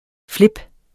Udtale [ ˈfleb ]